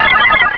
Cri de Spinda dans Pokémon Rubis et Saphir.